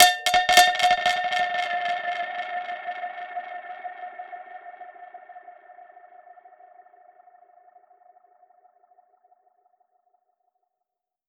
Index of /musicradar/dub-percussion-samples/85bpm
DPFX_PercHit_C_85-10.wav